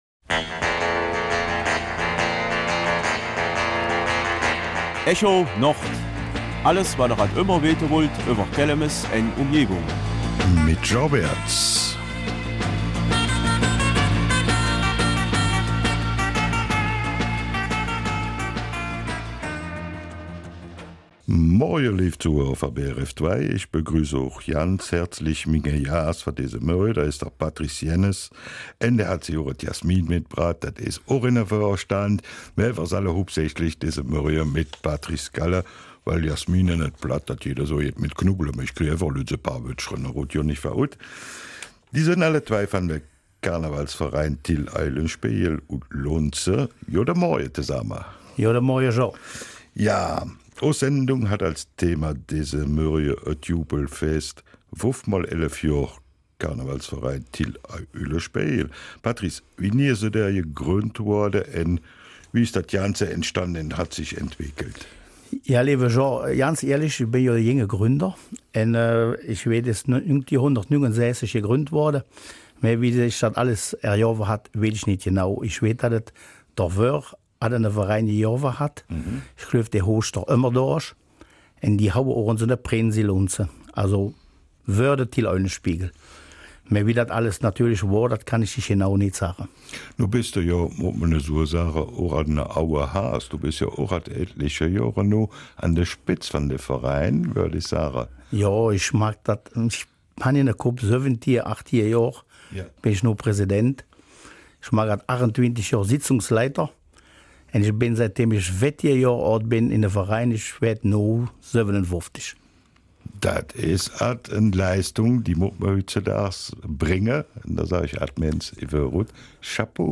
Kelmiser Mundart: 5x11 Jahre - Jubelfest beim KV Till Eulenspiegel